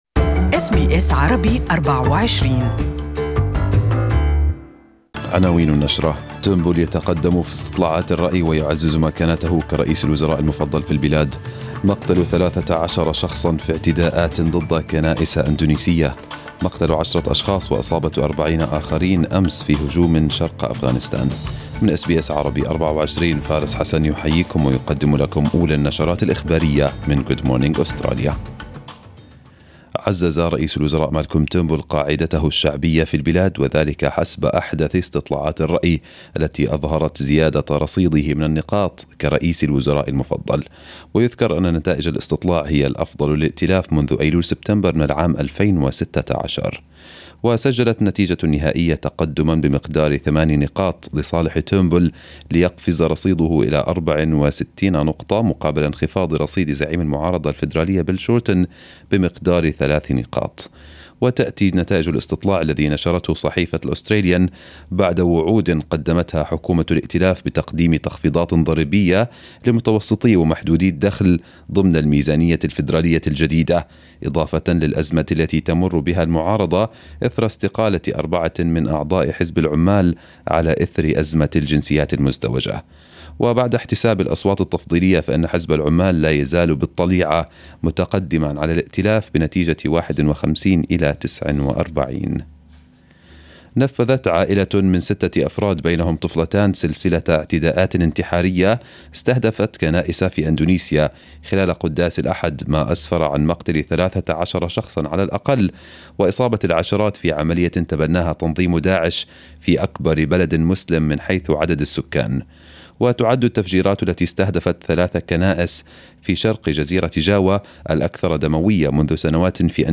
Arabic News Bulletin 14/05/2018